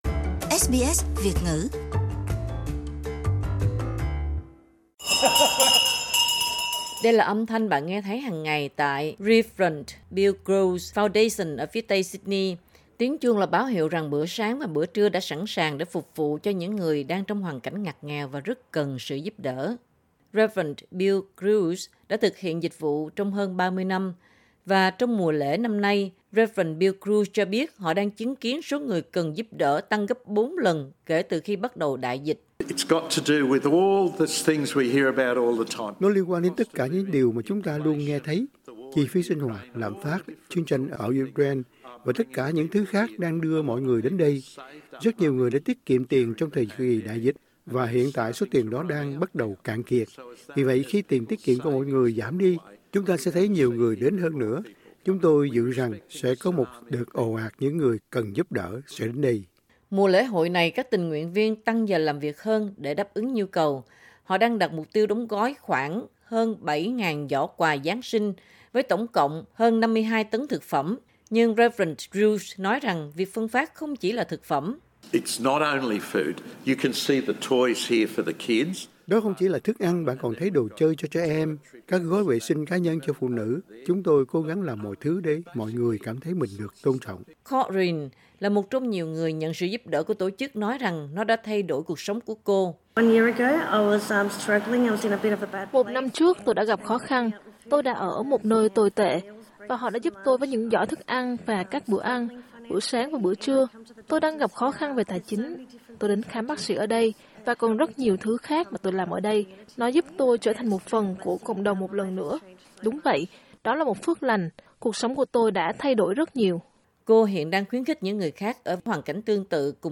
Tiếng chuông báo hiệu rằng bữa sáng và bữa trưa đã sẵn sàng để phục vụ cho những người đang trong hoàn cảnh ngặt nghèo và rât cần sự giúp đỡ.